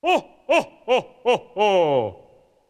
003 santa claus_ho ho ho.wav